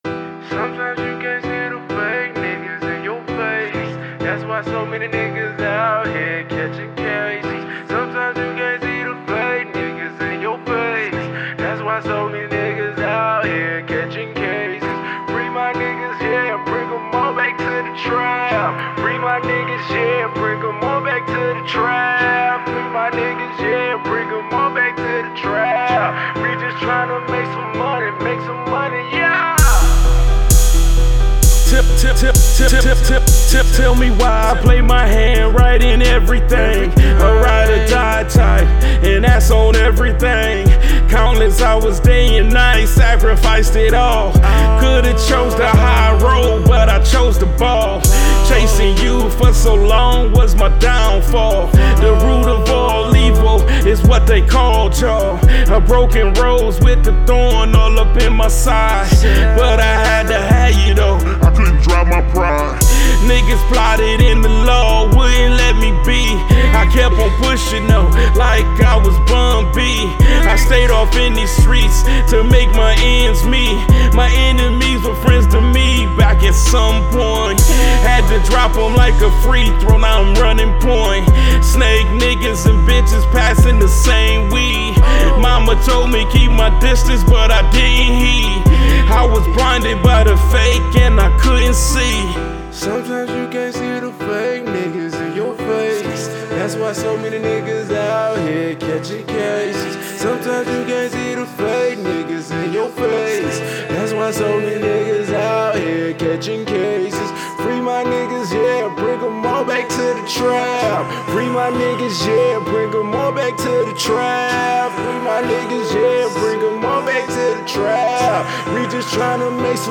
Indie